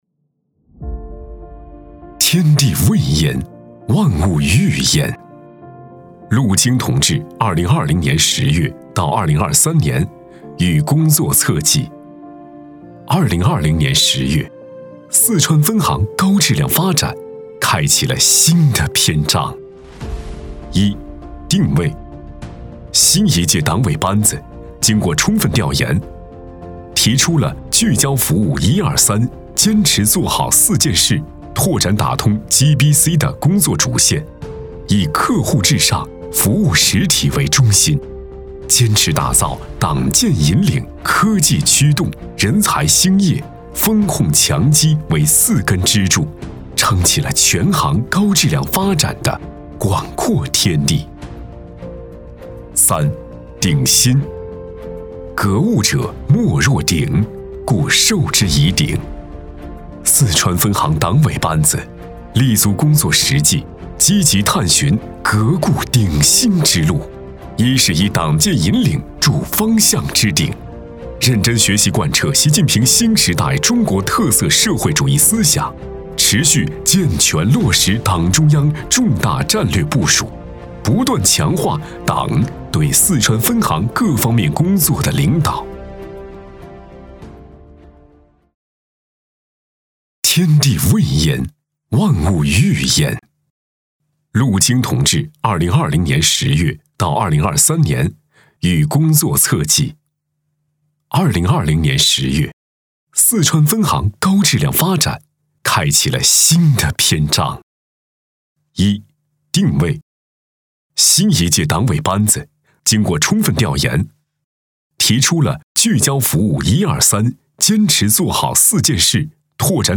特点：科技大气 商务磁性 激情力度
风格:时尚配音
大气专题-天地位焉 万物育试音.mp3